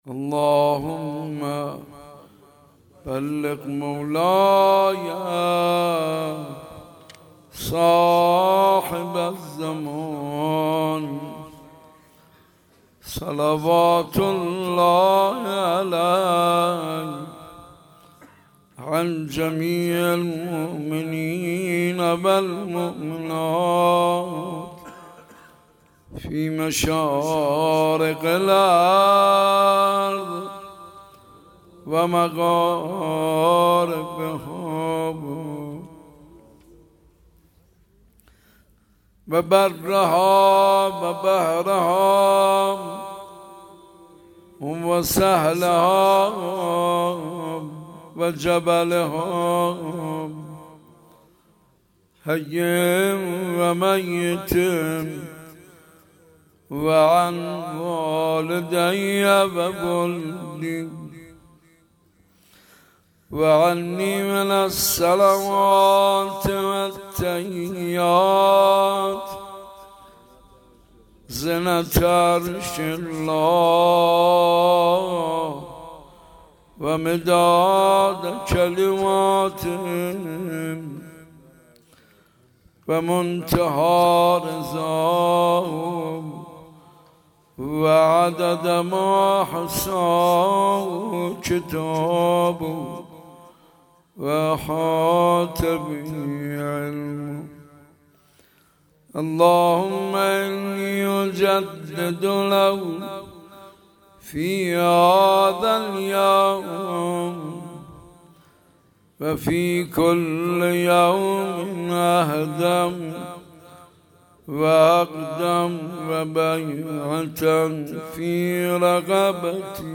6 آذر 97 - حسینیه صنف - زشتم ولی نگاه به نیکو سرشت می ارزد